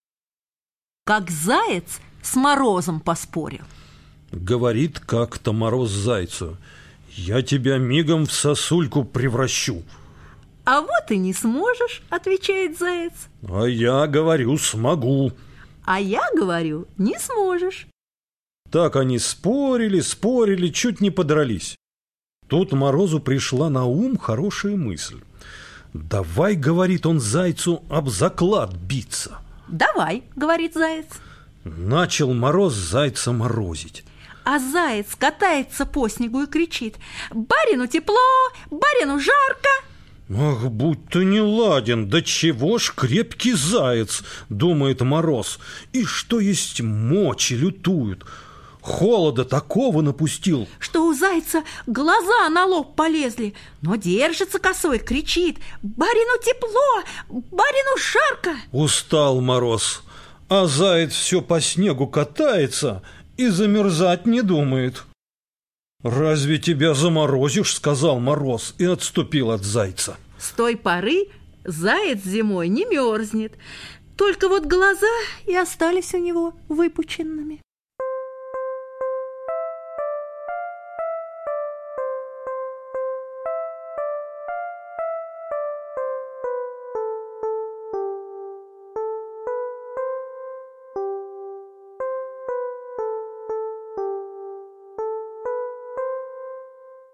Как заяц с морозом поспорил - латышская аудиосказка - слушать онлайн